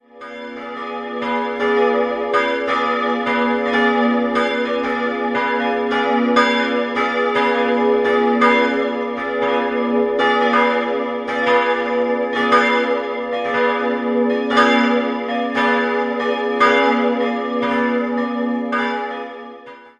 Wann genau der quadratische Turm mit seinem achteckigen Aufsatz errichtet wurde, ist nicht exakt bekannt. Verminderter Dreiklang: gis'-h'-d'' Alle Glocken wurden im Jahr 1921 vom Bochumer Verein für Gussstahlfabrikation gegossen.